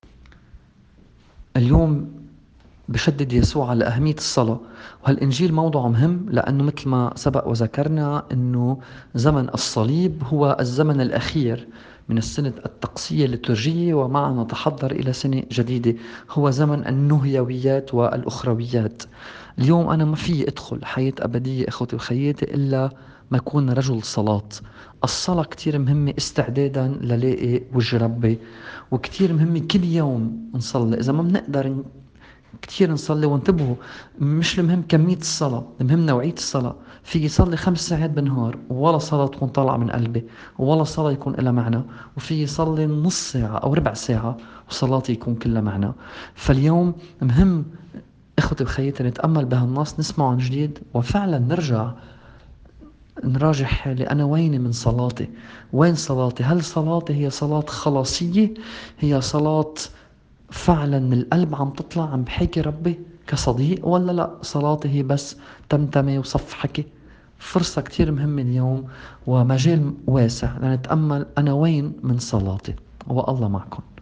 تأمّل في إنجيل يوم ٨ تشرين الأول ٢٠٢٠.mp3